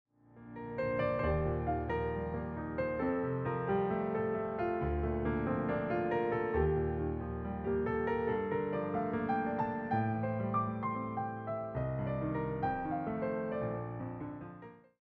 reimagined as solo piano pieces